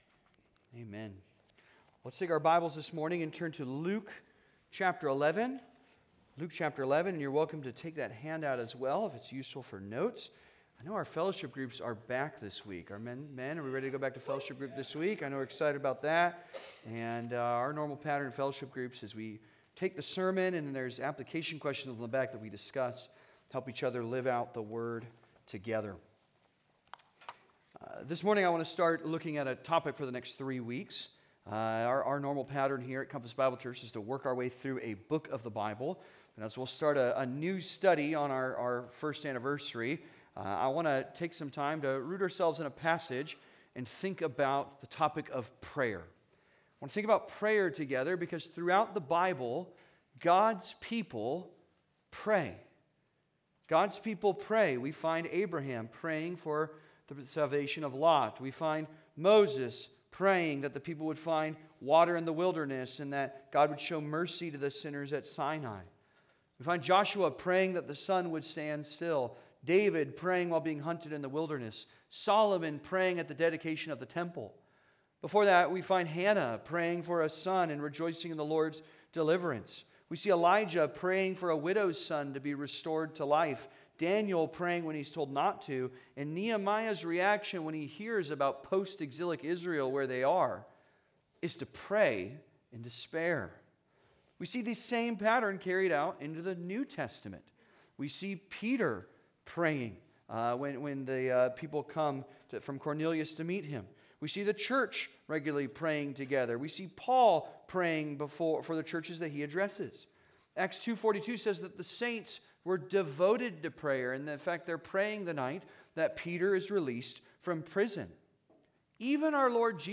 Praying Together: Our Father (Sermon) - Compass Bible Church Long Beach